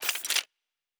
Weapon 03 Reload 2.wav